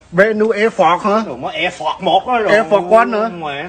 Vietnamese Air Force One accent